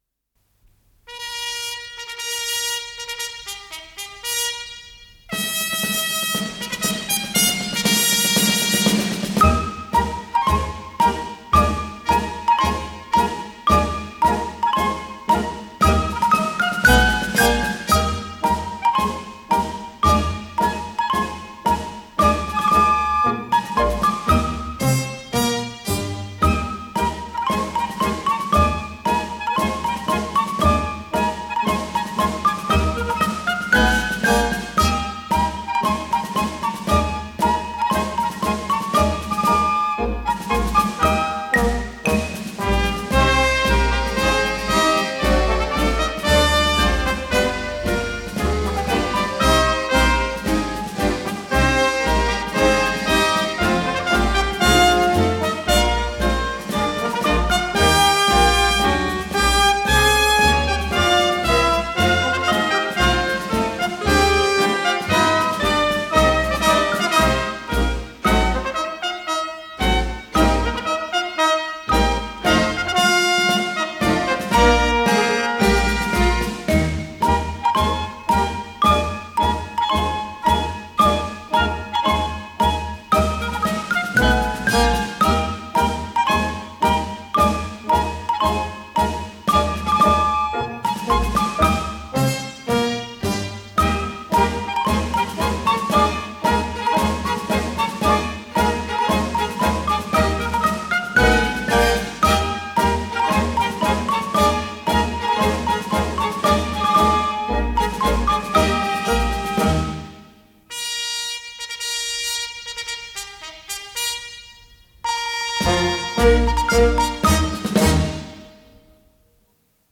с профессиональной магнитной ленты
ПодзаголовокСюита для инструментального ансамбля